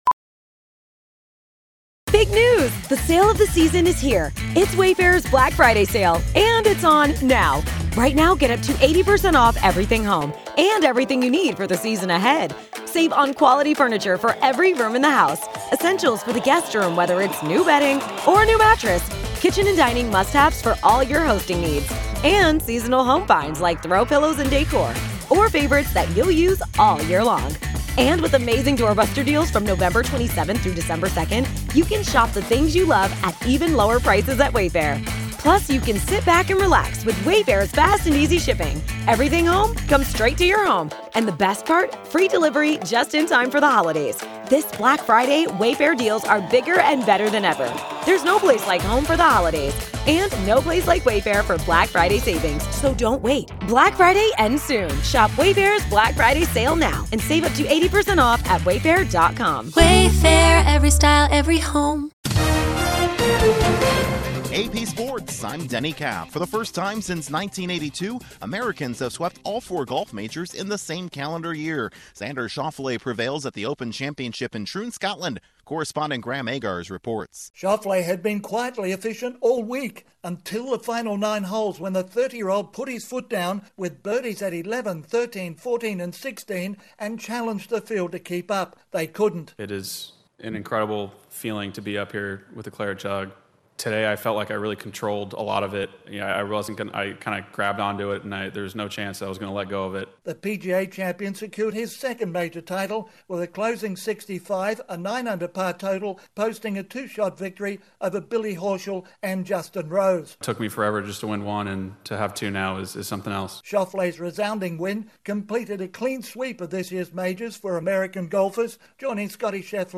An American wins another Golf major, Kyle Larson vaults atop the NASCAR leaderboard and an Astros slugger hits for the cycle in a losing effort. Correspondent